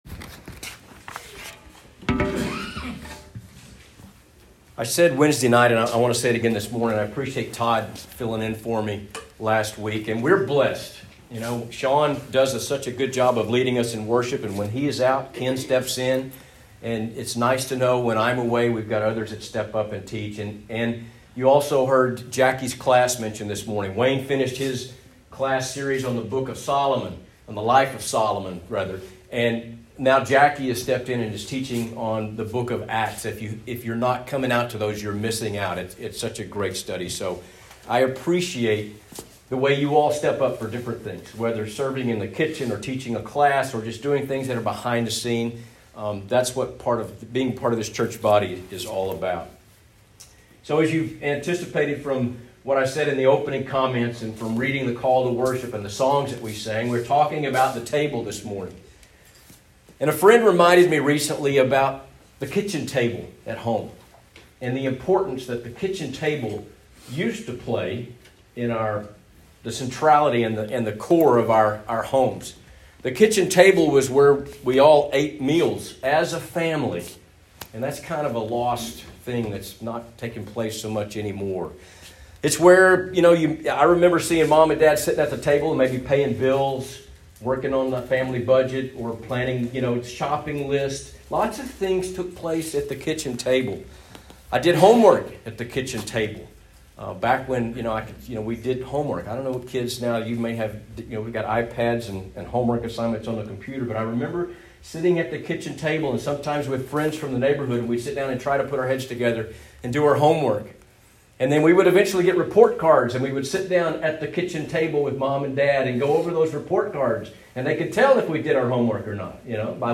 Sermons | Buffalo Gap Church of Christ